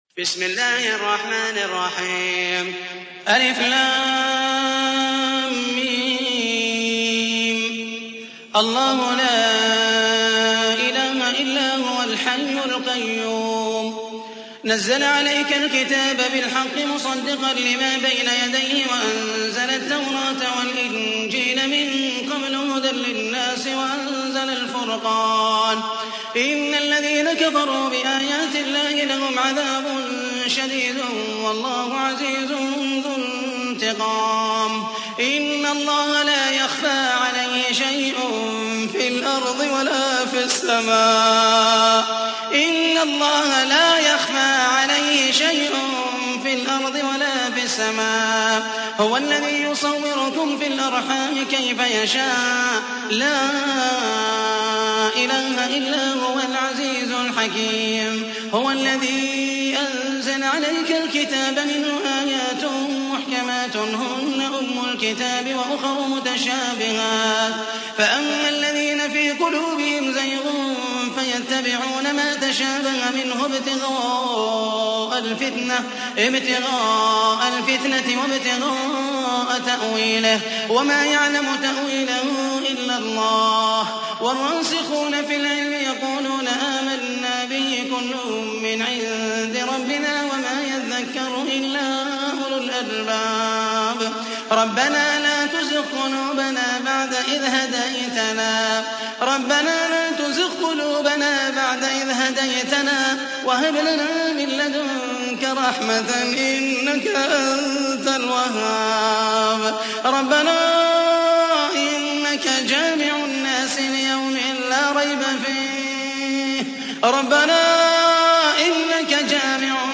تحميل : 3. سورة آل عمران / القارئ محمد المحيسني / القرآن الكريم / موقع يا حسين